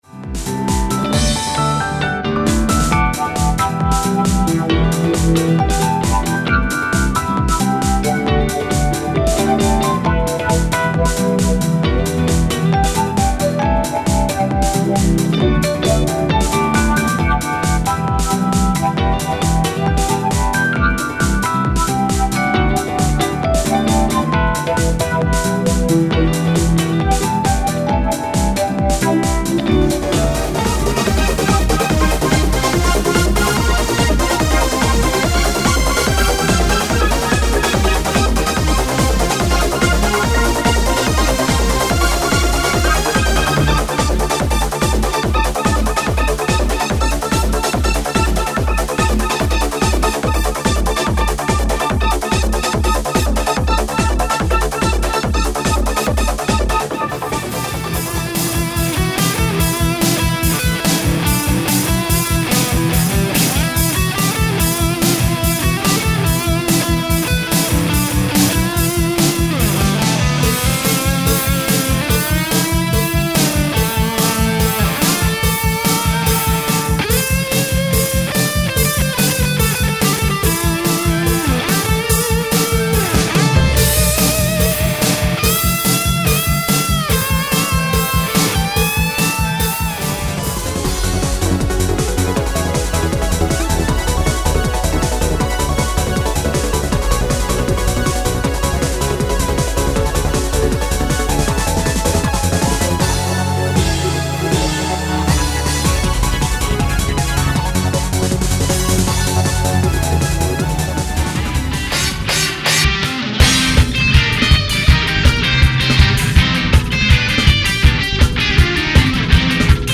初・東方アレンジ集・第１弾：
ep soft house mix
eu-tech mix
melodic spell mix
bigbeat gtr mix
loud metal mix
クロスフェードデモ：
演奏楽器はキーボード、ベース。
演奏楽器は主にギター。